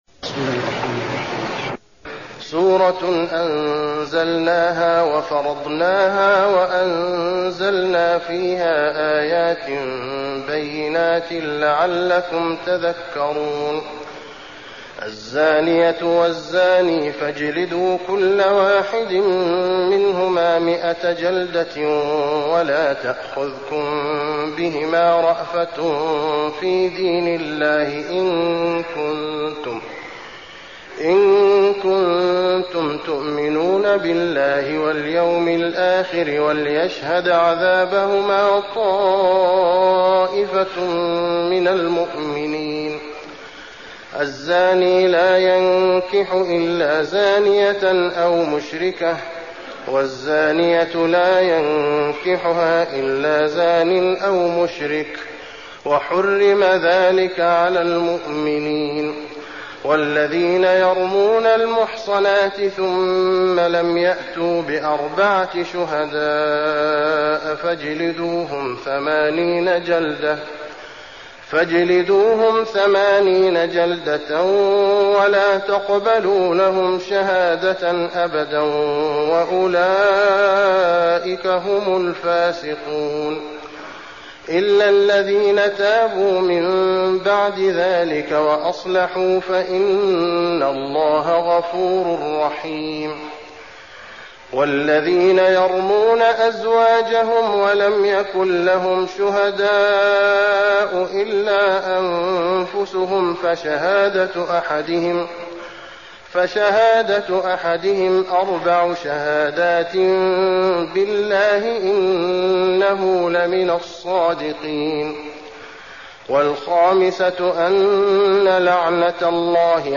المكان: المسجد النبوي النور